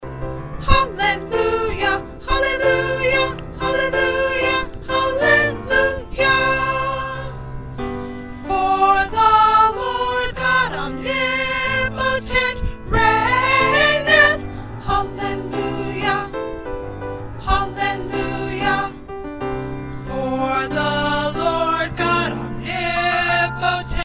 They also split on the final note of the song.